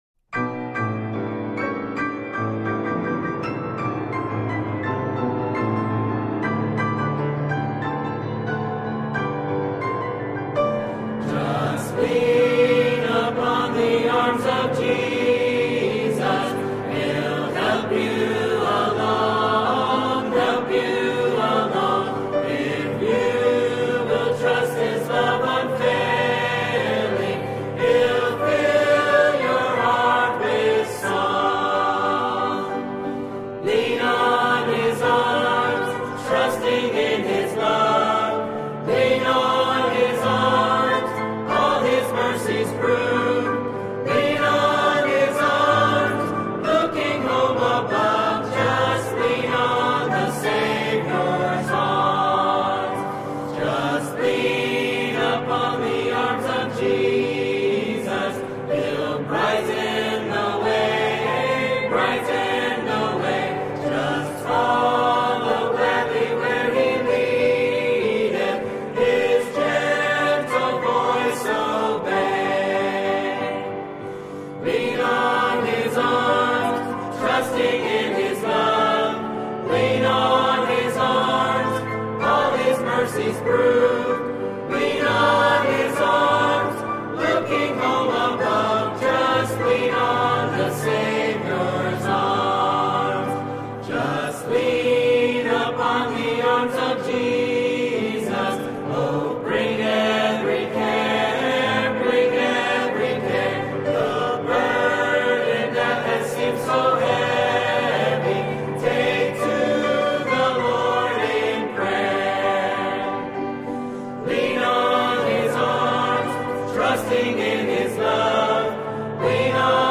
Music Hymns